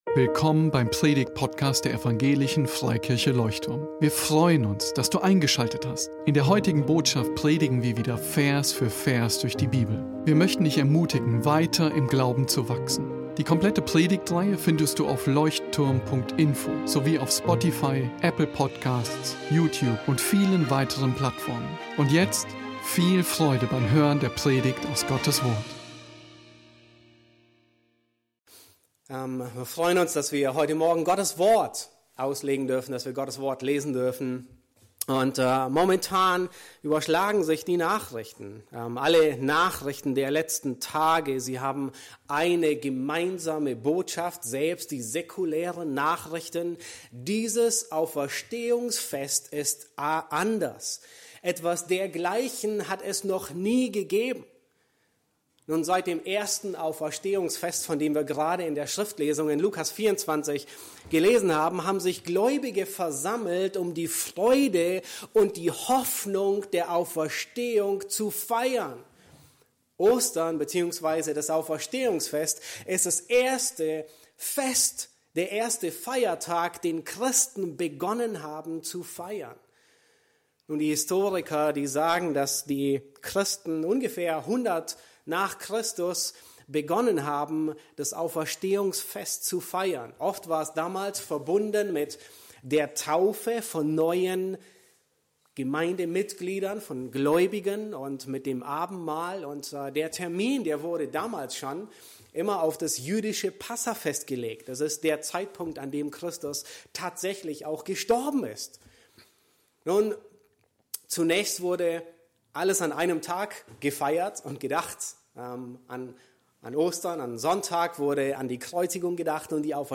Beschreibung vor 6 Jahren Predigt am Ostersonntag zur Textstelle von 1.Korinther 15,1-58 Predigtgliederung: 1.DIE AUFERSTEHUNG JESU IST EINE TATSACHE 2. DIE AUFERSTEHUNG JESU IST UNENTBEHRLICH 3.